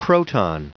Prononciation du mot proton en anglais (fichier audio)
Prononciation du mot : proton